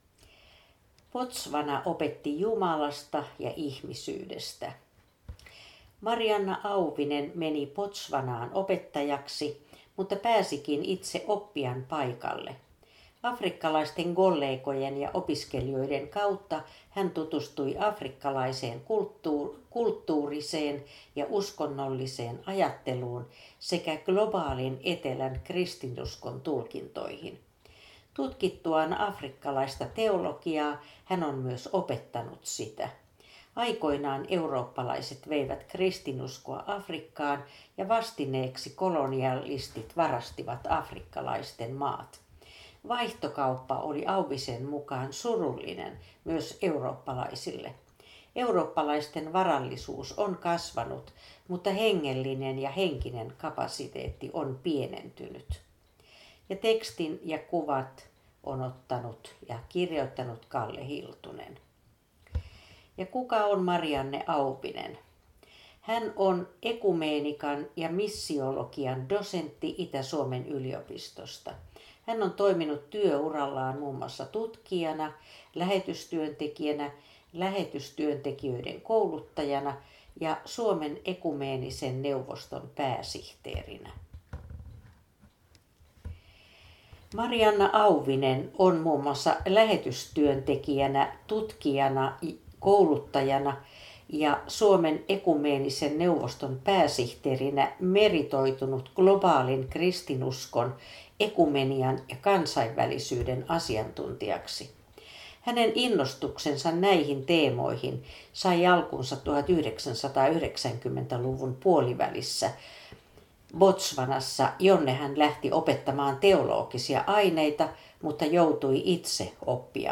Kuuntele artikkeli luettuna.